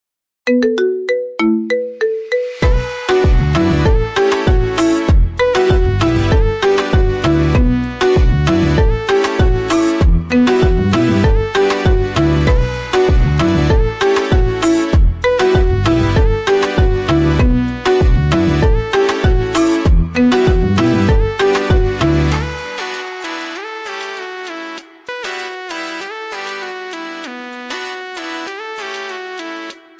iPhone Marimba
Remix